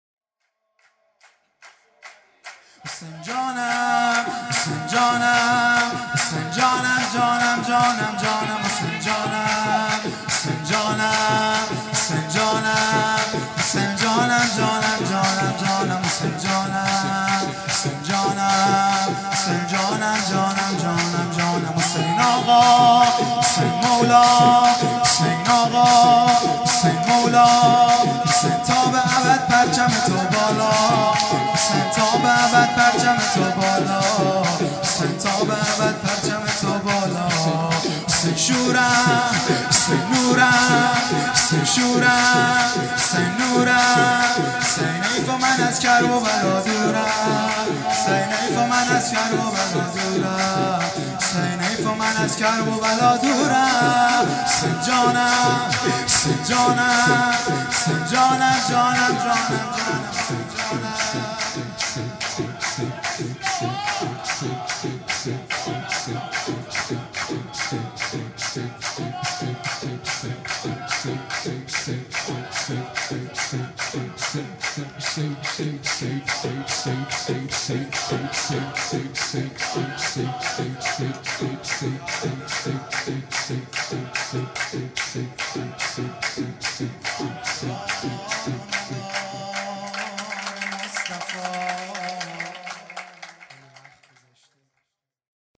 سرود
مبعث رسول اکرم(ص)|21 فروردین 1397